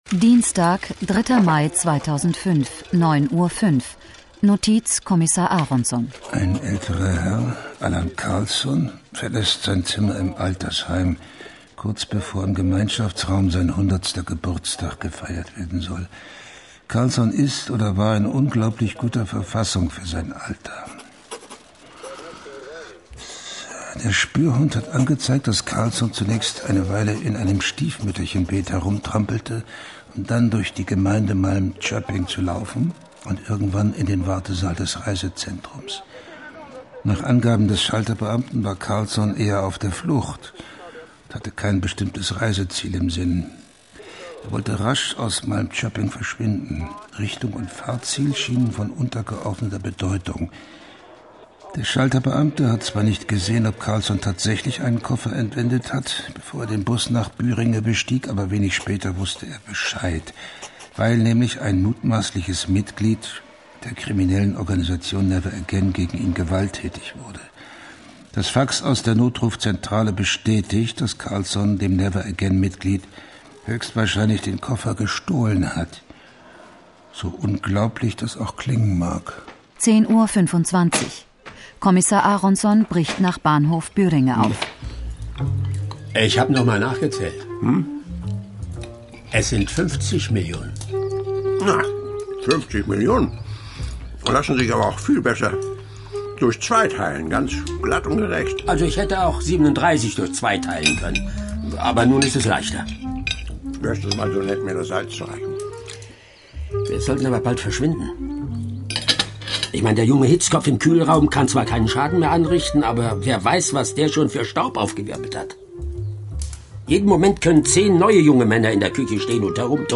Lese- und Medienproben